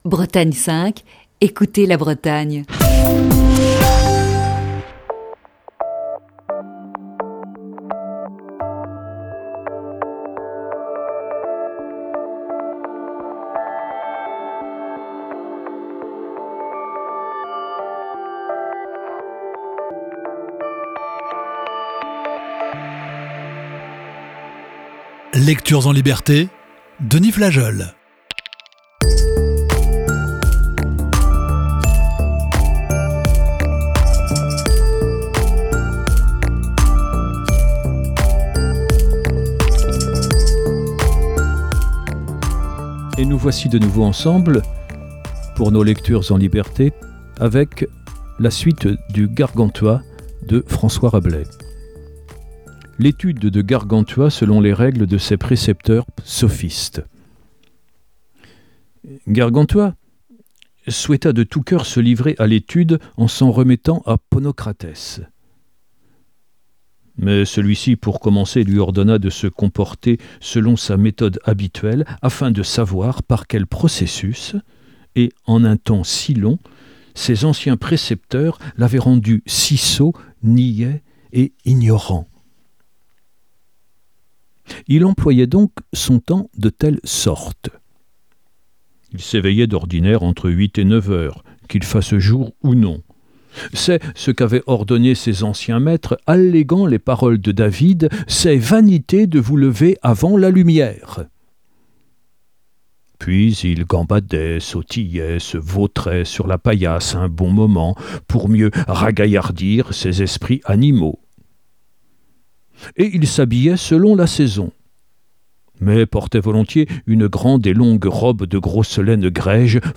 Émission du 28 octobre 2020.